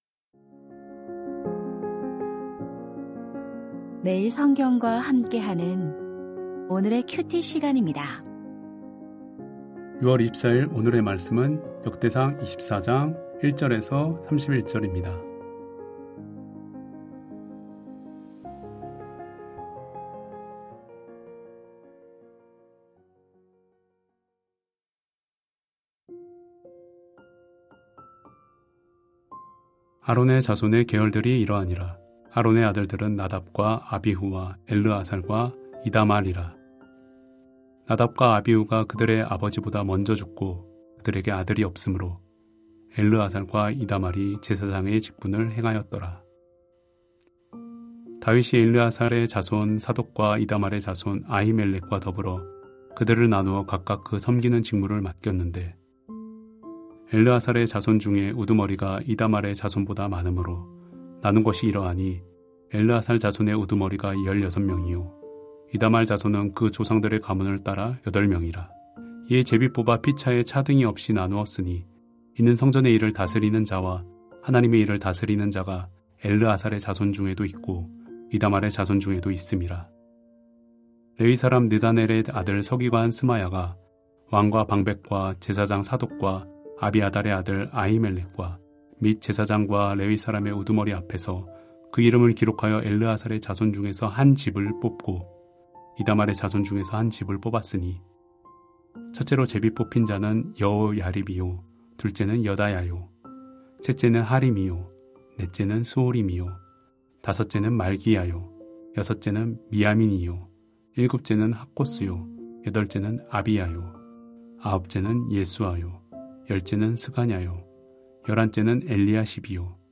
역대상 24:1-31 잊지 말고 기억하자 2025-06-24 (화) > 오디오 새벽설교 말씀 (QT 말씀묵상) | 뉴비전교회